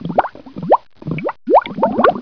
pearl into the ocean
Category: Sound FX   Right: Personal